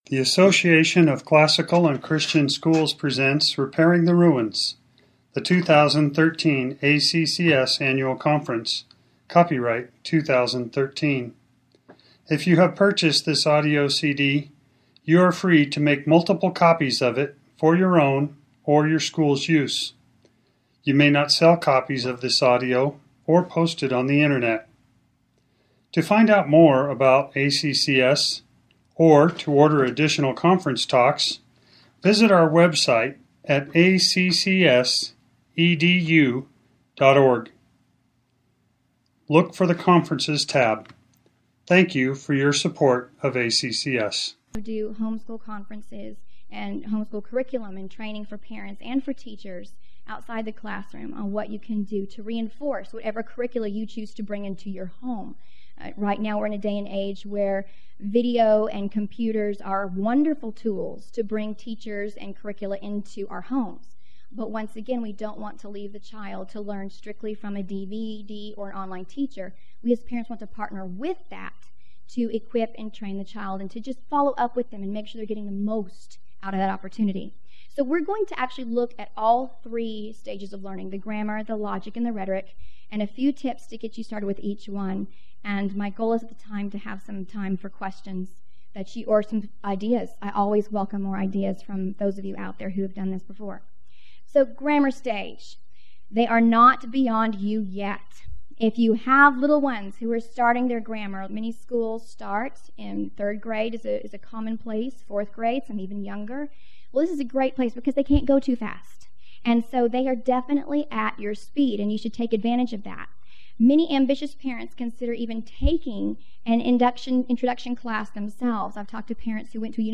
2013 Workshop Talk | 0:58:46 | All Grade Levels, Latin, Greek & Language
Jan 19, 2019 | All Grade Levels, Conference Talks, Latin, Greek & Language, Library, Media_Audio, Workshop Talk | 0 comments